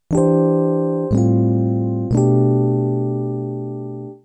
Classic voicing: 3rd, 2nd, 5th, 1st
Instead, this voicing of the type II mu-major sounds more open and jazzy. Note that you get two stacked 4ths at the top, contributing to the jazzy sound of the chord.
G mu major